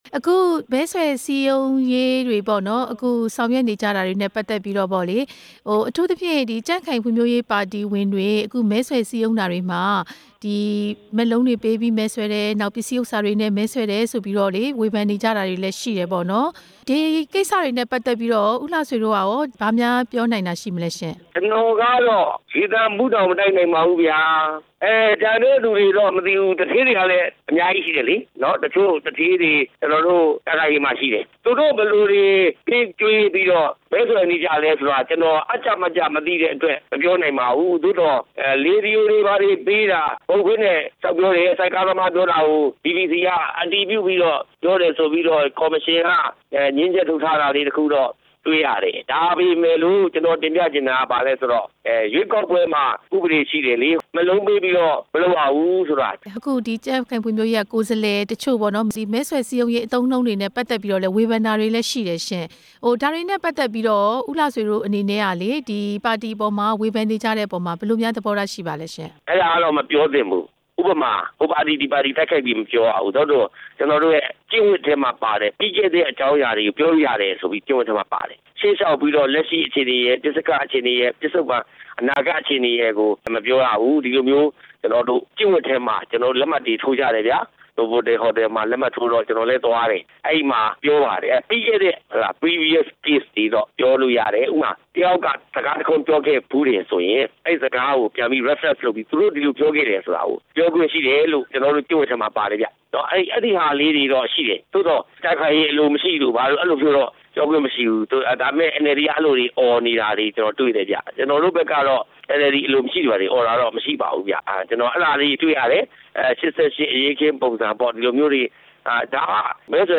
လွှတ်တော်ကိုယ်စားလှယ် ဦးလှဆွေ ကို မေးမြန်းချက်